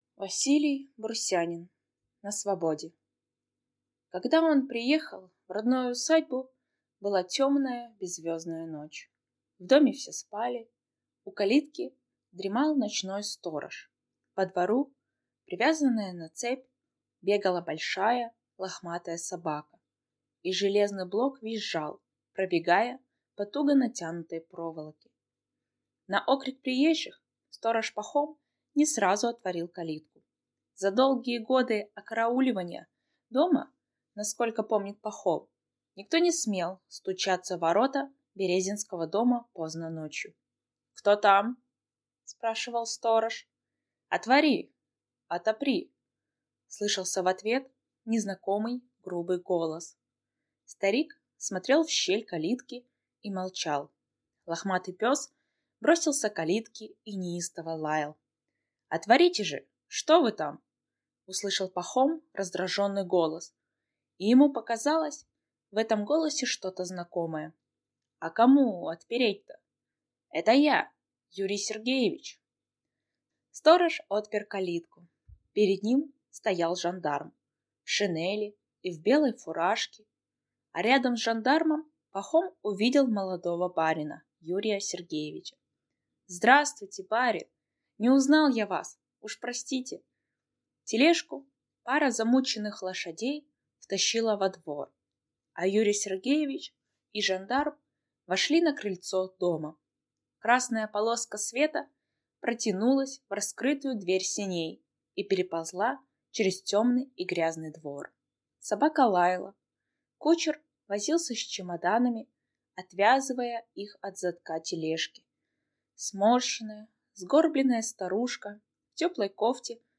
Aудиокнига На свободе